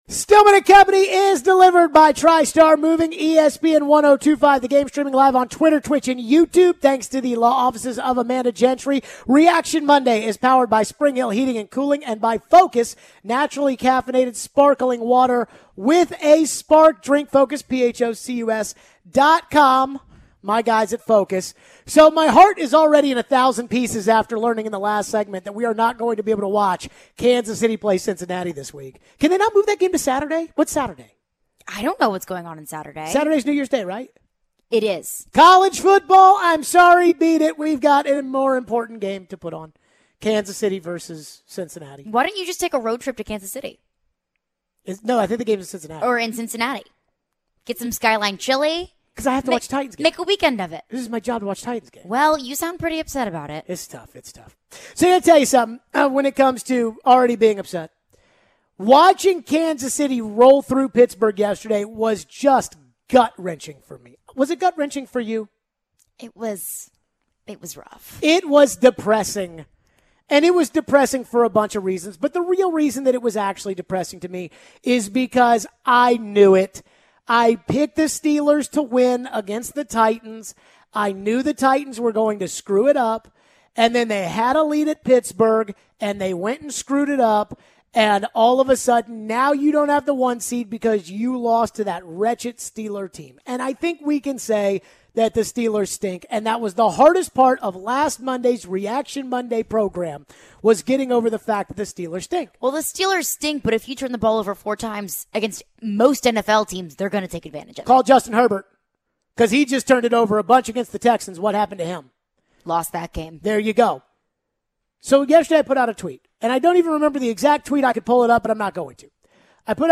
Do we think fans are making too many excuses for Tannehill? Some breaking news as the Titans add some more to the COVID list. We take your phones.